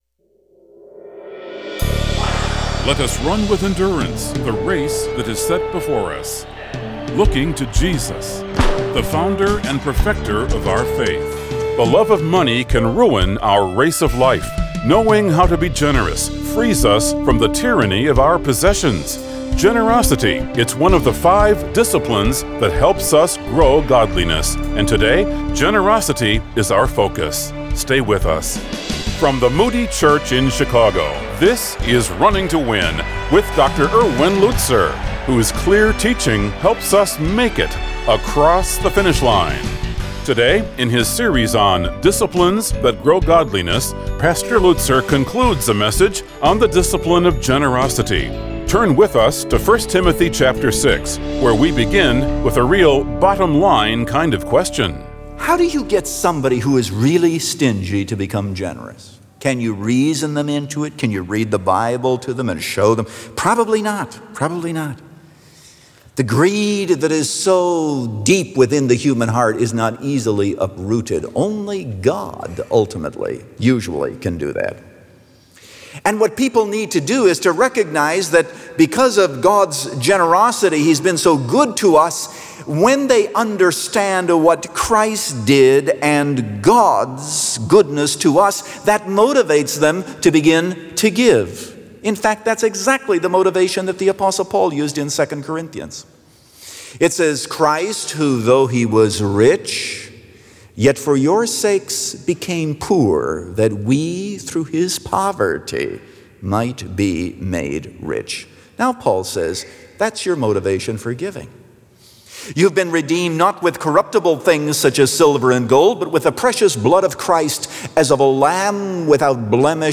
The Discipline Of Generosity – Part 4 of 4 | Radio Programs | Running to Win - 15 Minutes | Moody Church Media